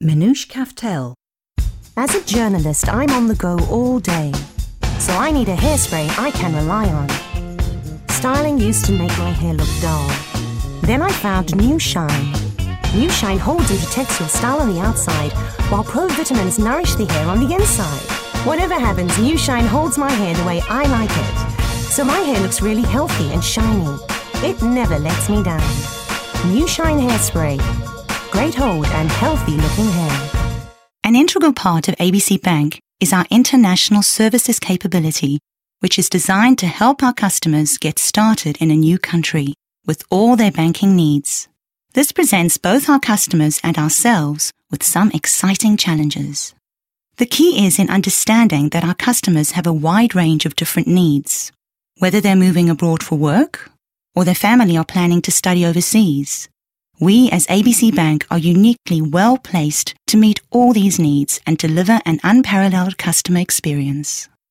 contact her agent direct for female South African voice overs, recordings and session work.